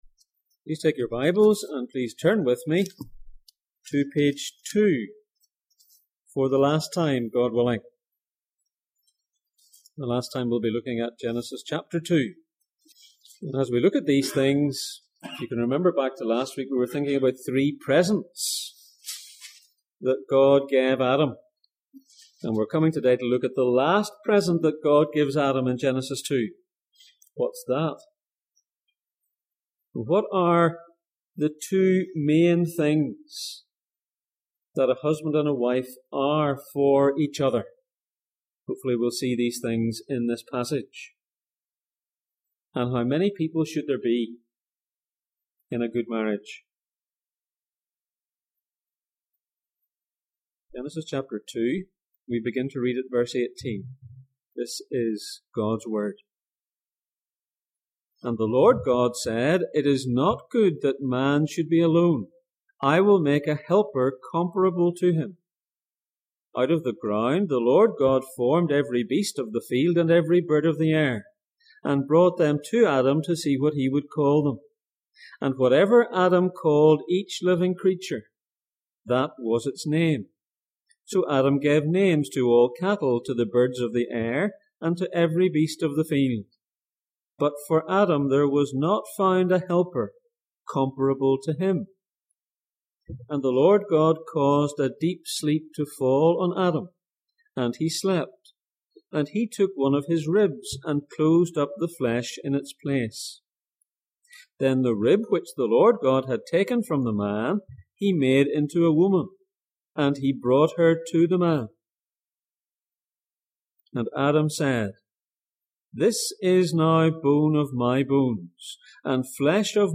Back to the beginning Passage: Genesis 2:18-25, Ephesians 5:25 Service Type: Sunday Morning